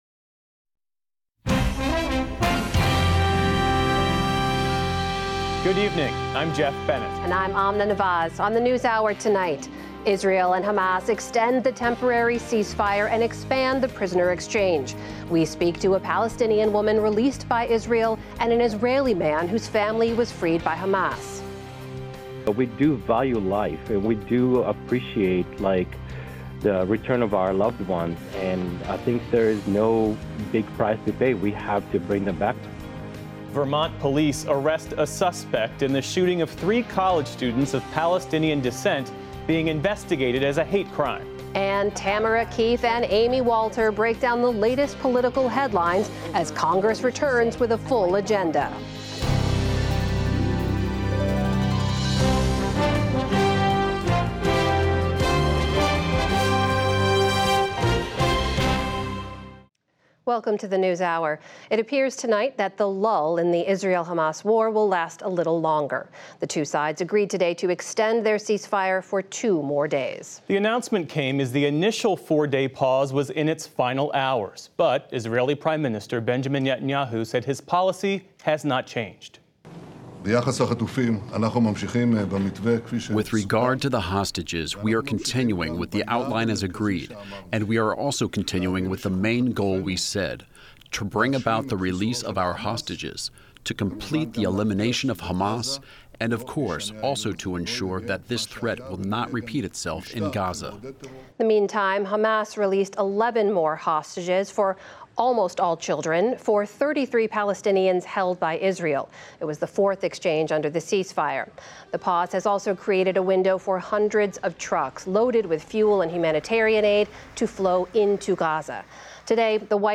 Listen to the complete PBS News Hour, specially formatted as a podcast. Published each night by 9 p.m., our full show includes every news segment, every interview, and every bit of analysis as our television broadcast.